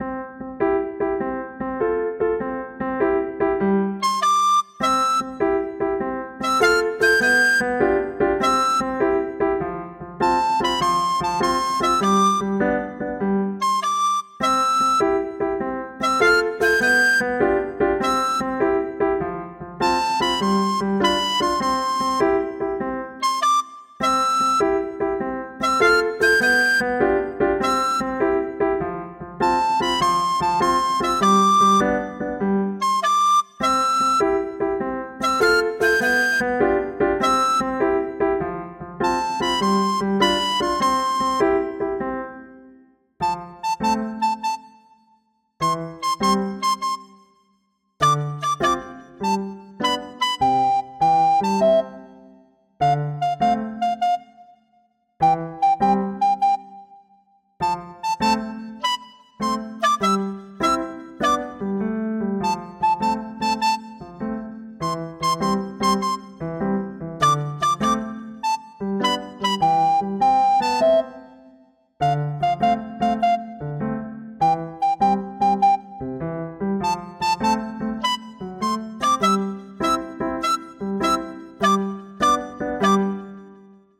ファンタジー系フリーBGM｜ゲーム・動画・TRPGなどに！
無限ループ推奨。